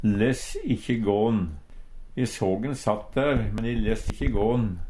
Sjå òg less ikkje vaLe (Nore) Høyr på uttala Ordklasse: Uttrykk Kategori: Uttrykk Attende til søk